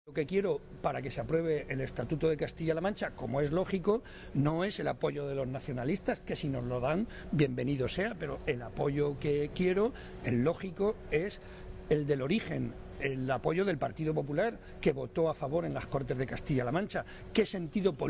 Momento de la clausura del Foro Joven de JSCM